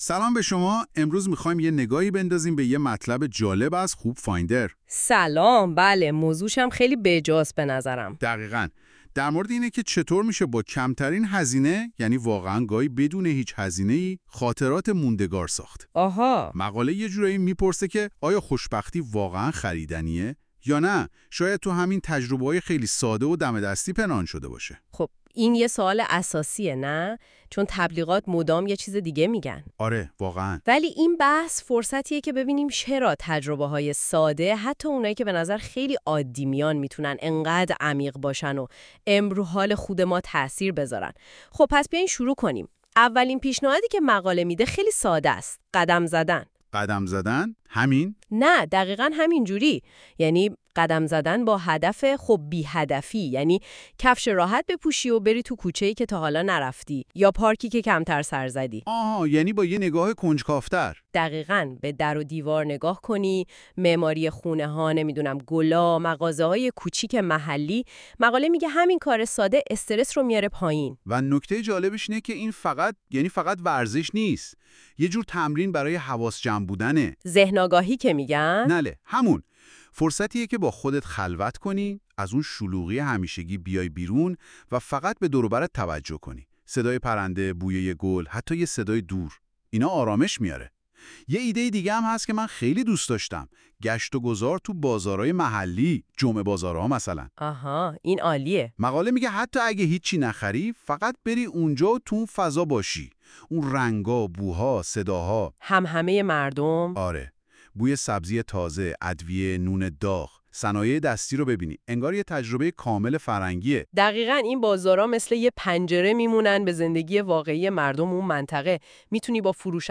🎧 خلاصه صوتی بهترین تجربه های ارزان ولی فراموش نشدنی
این خلاصه صوتی به صورت پادکست و توسط هوش مصنوعی تولید شده است.